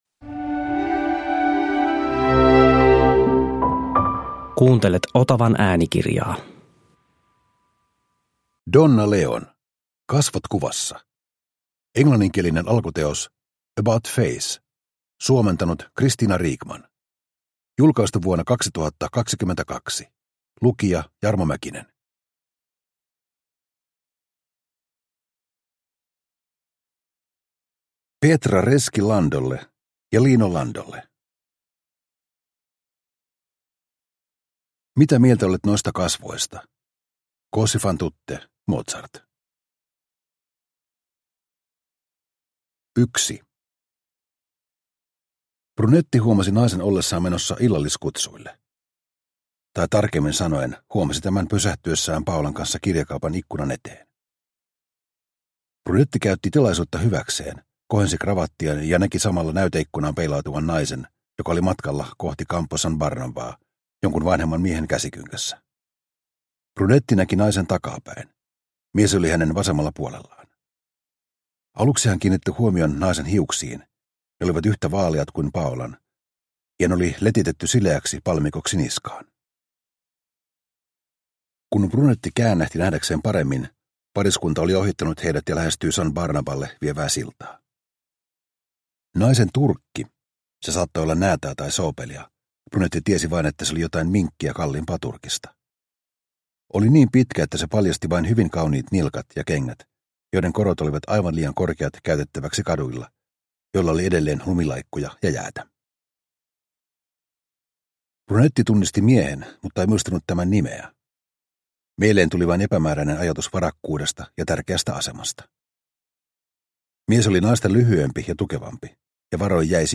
Kasvot kuvassa – Ljudbok – Laddas ner
Uppläsare: Jarmo Mäkinen